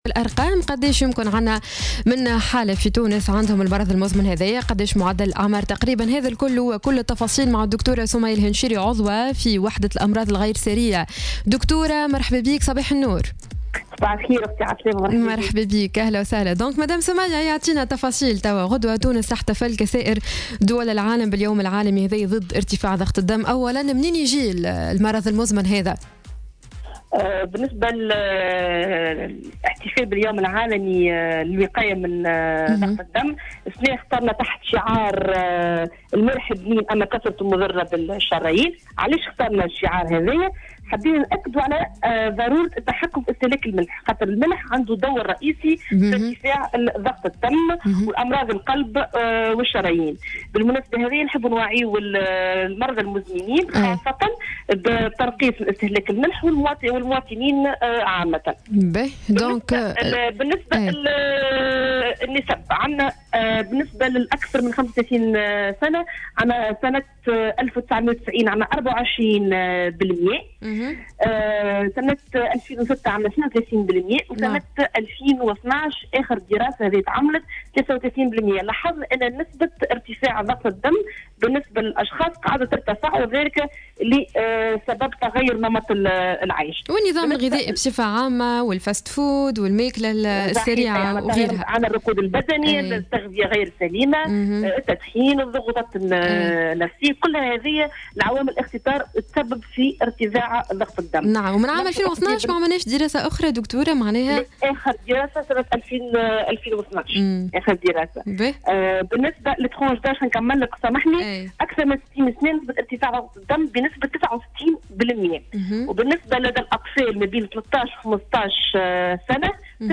مداخلة